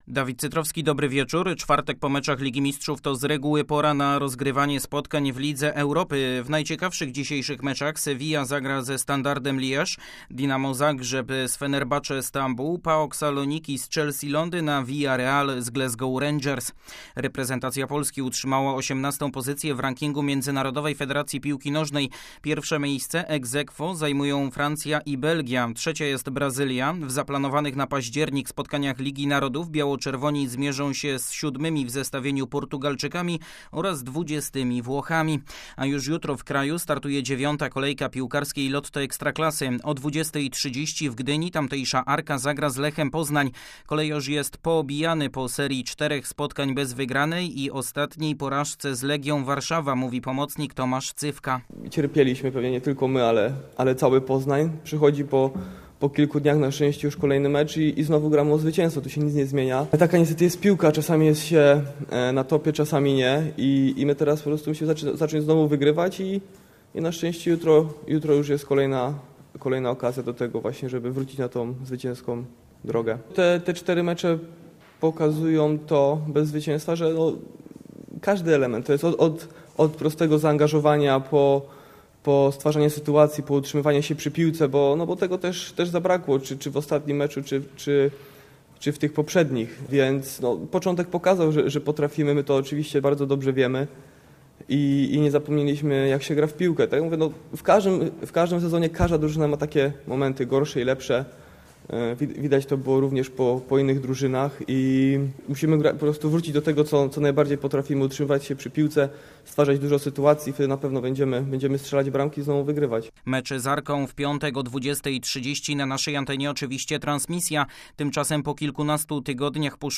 20.09. serwis sportowy godz. 19:05